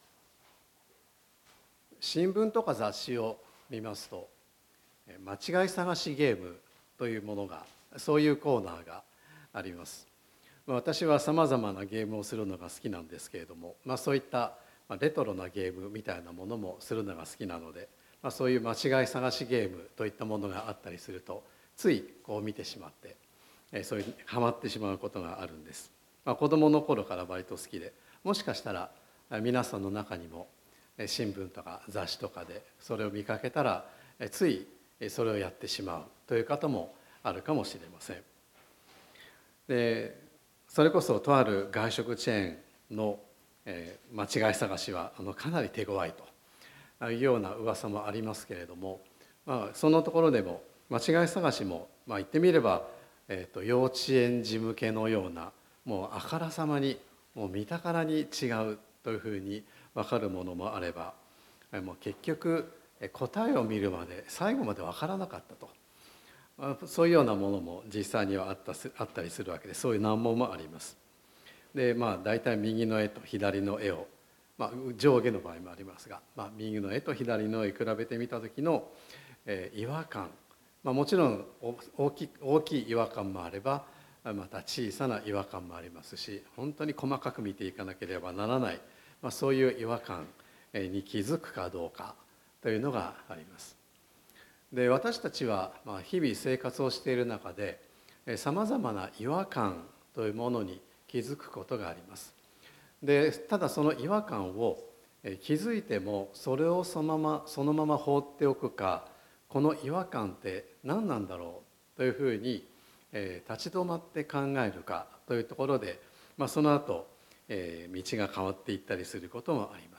９月22日伝道礼拝
「実がなるまで待つ神さまの愛」9月22日礼拝説教